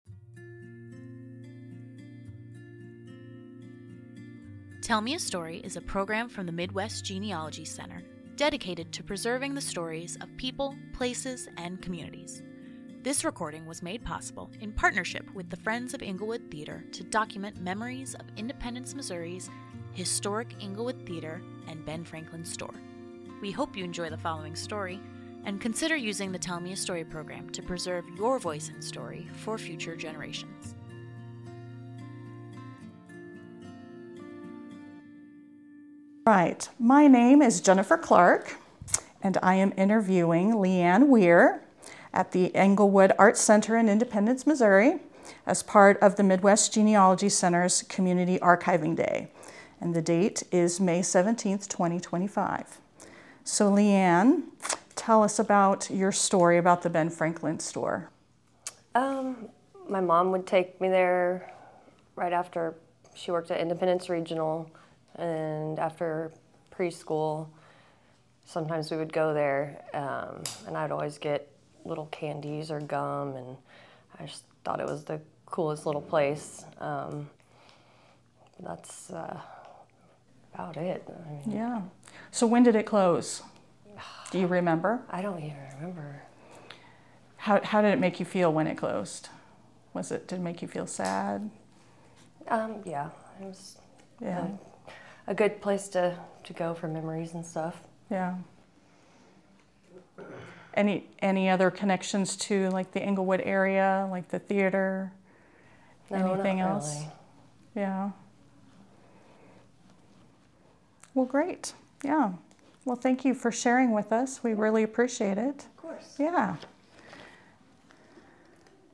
Englewood Theater Community Archiving Day - Oral Histories
interviewer
interviewee